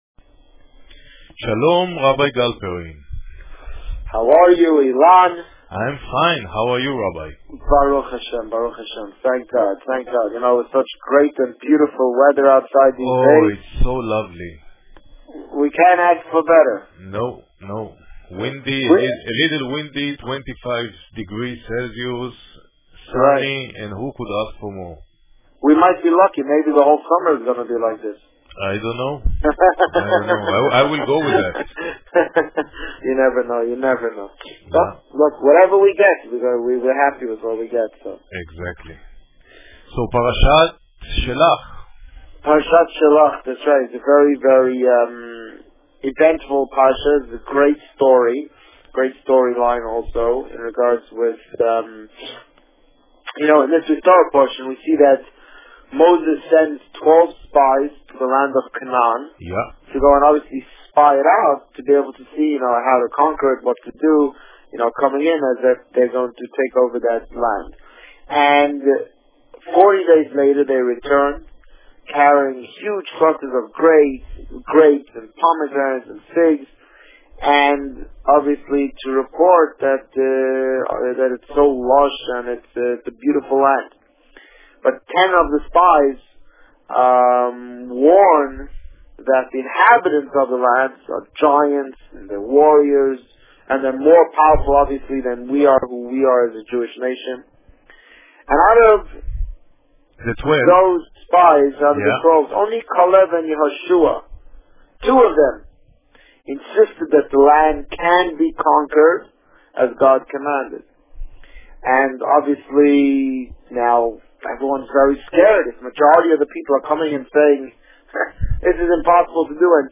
You are here: Visitor Favourites The Rabbi on Radio The Rabbi on Radio Parsha Shelach Published: 16 June 2011 | Written by Administrator On June 16, 2011, the Rabbi spoke about Parsha Shelach.